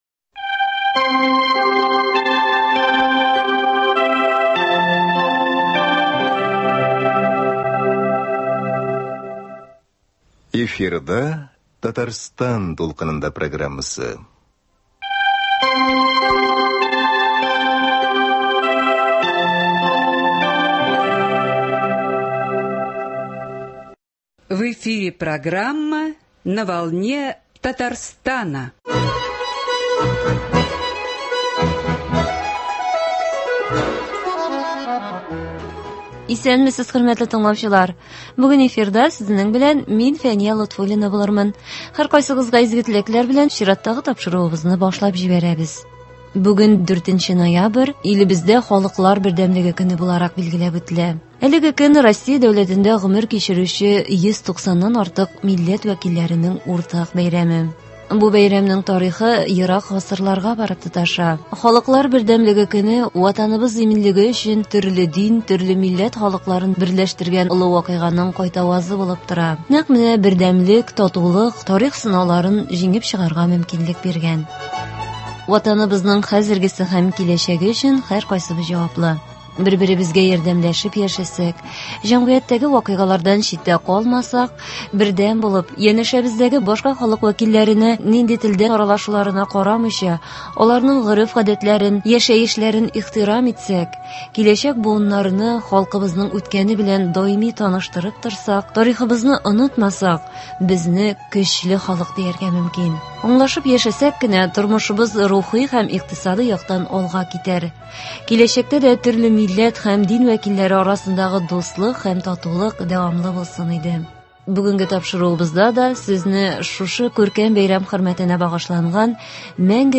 Бүгенге тапшыруыбызда сезне шушы күркәм бәйрәм хөрмәтенә багышланган “Мәңгегә бергә” дип аталган әдәби-музыкаль композиция тыңларга чакырабыз. Без аны дуслык туганлык, бердәмлек турындагы шигырьләрдән һәм җырлардан төзедек.